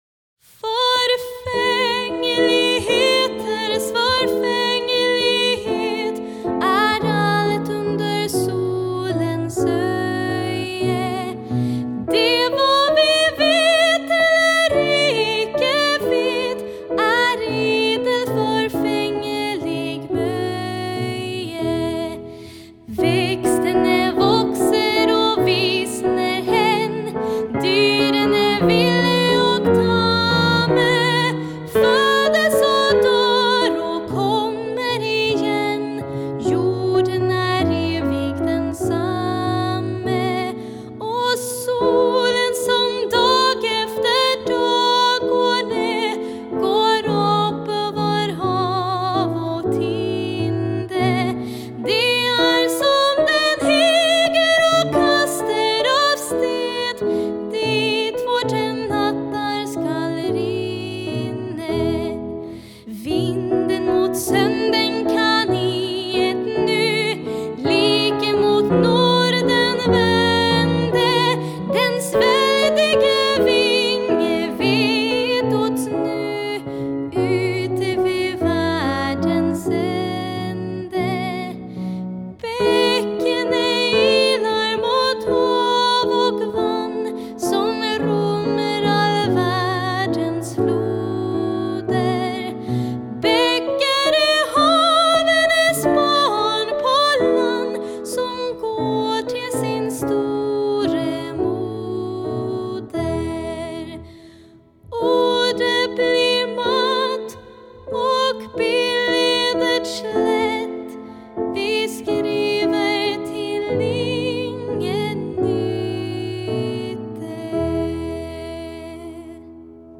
Piano och arr